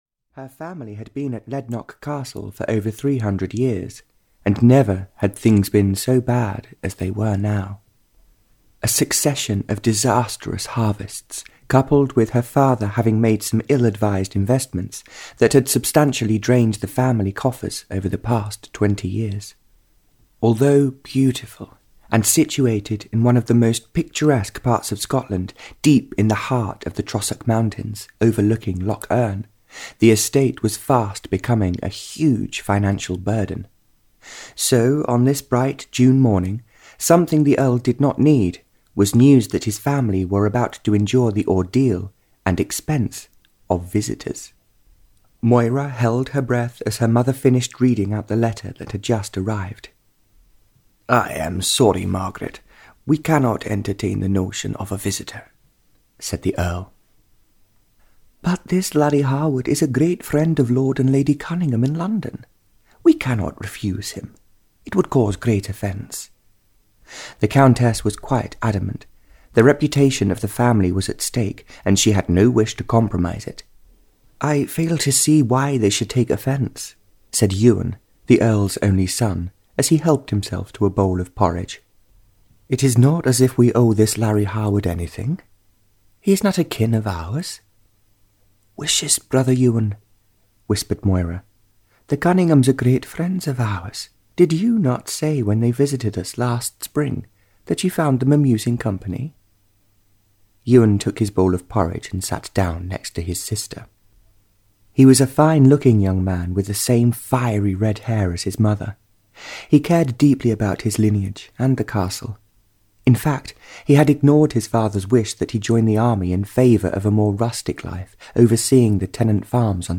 The Richness of Love (Barbara Cartland’s Pink Collection 31) (EN) audiokniha
Ukázka z knihy